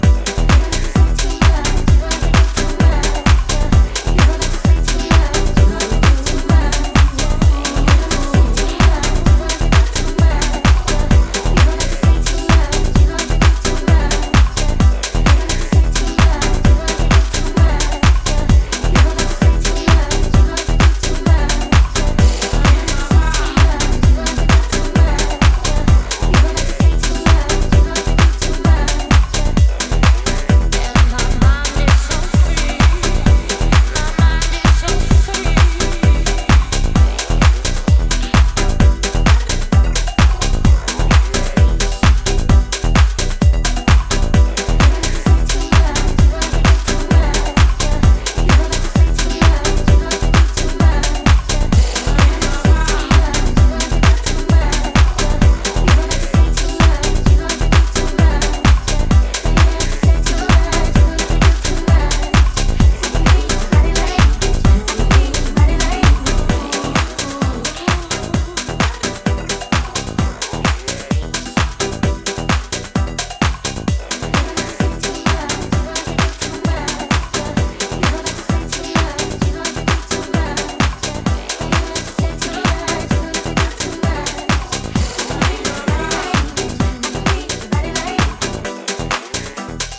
R&B系声ネタがキャッチーにフロアへと誘う
陽性なパーティーフレイヴァがこれからの季節にもぴったりな秀逸ハウスを展開